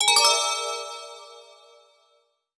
Sound_Ding.wav